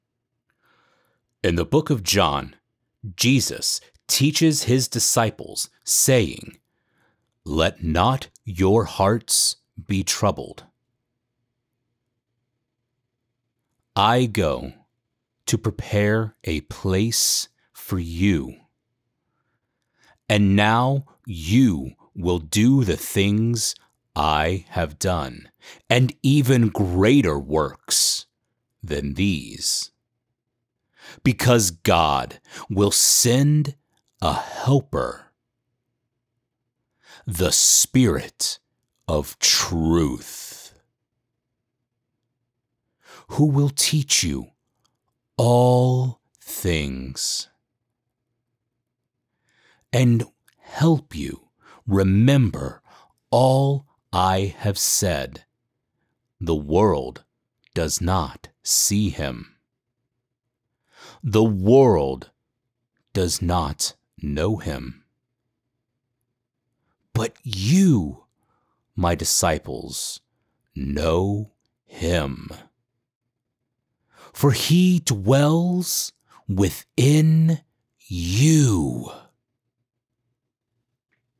Biblical Reading
Middle Aged